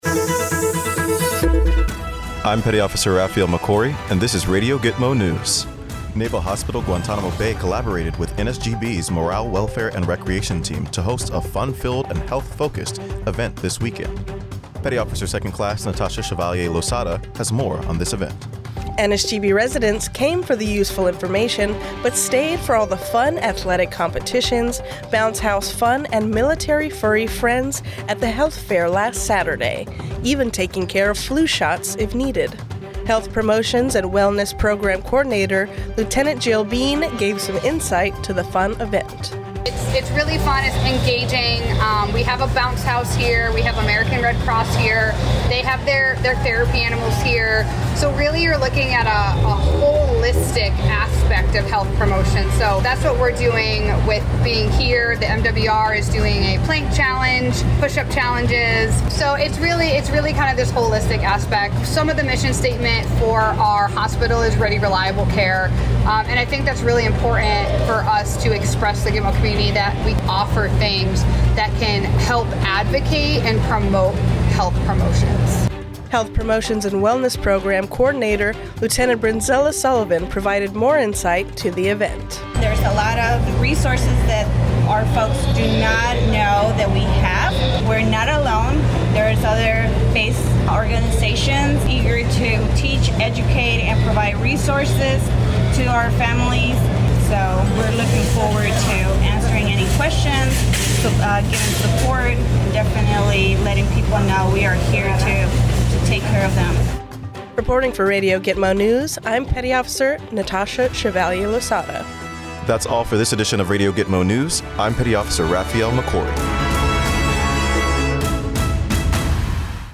A radio newscast informing GTMO residents about the Health Fair .